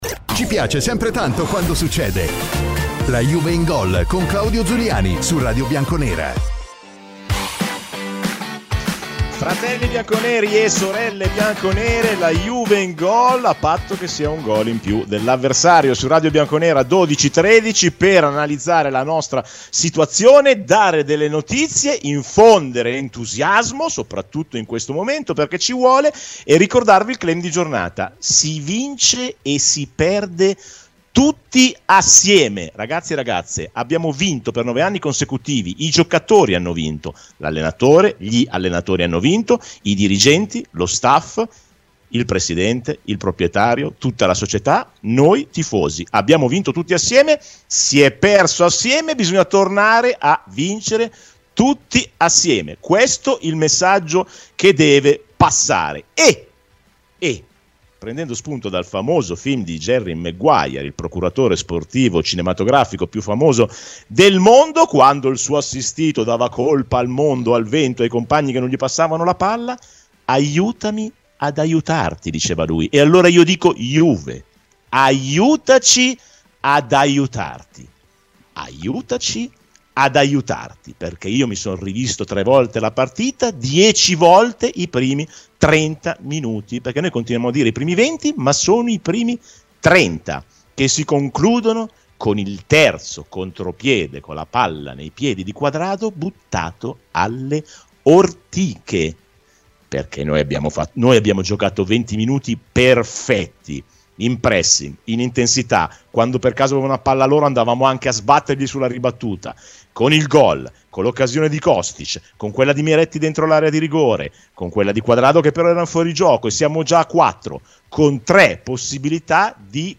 Ospiti : Fabrizio Ravanelli